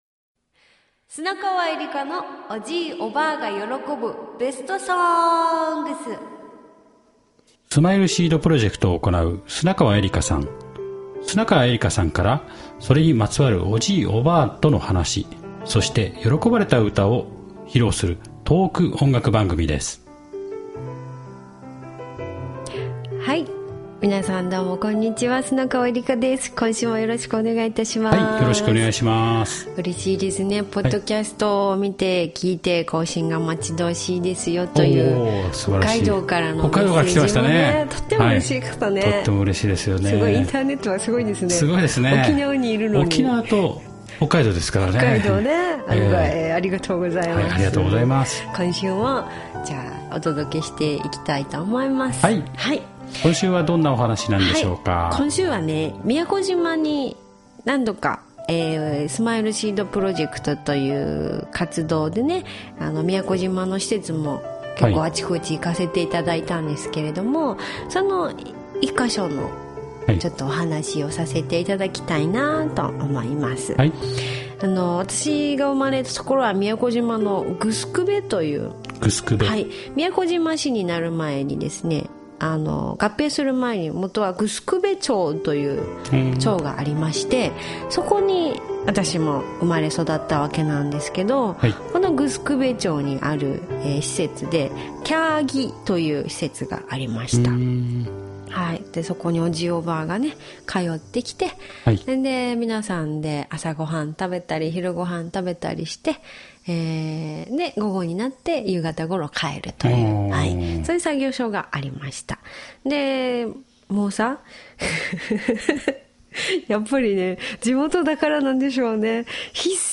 「私が守り育てるからね」宮古島独特の子守り制度から | 「ばんがむり(我んが守り)」宮古島民謡・子守唄 - ０６月２４日配信
スマイルシードプロジェクトで生まれ故郷の宮古島は城辺町で唄うことになった砂川恵理歌さん。
昔から知る懐かしい顔のいる中、恵理歌さん自身も昔を懐かしみながら歌った子守唄。